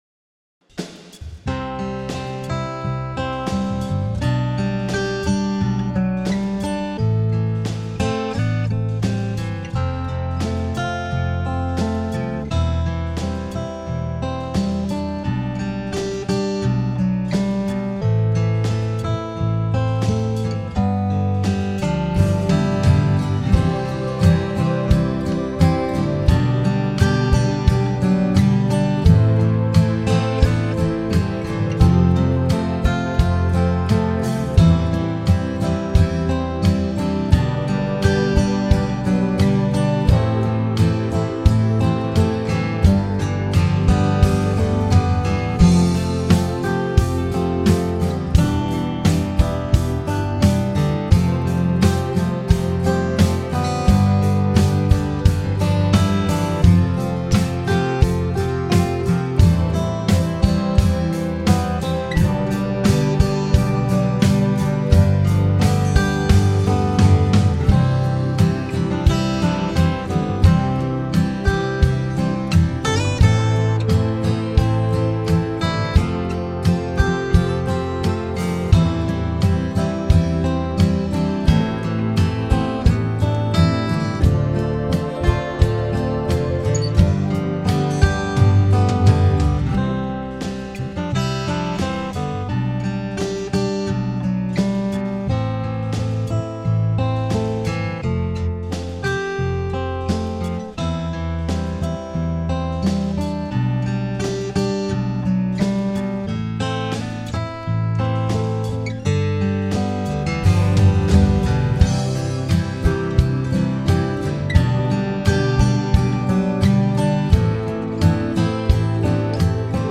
Home > Music > Rock > Bright > Smooth > Medium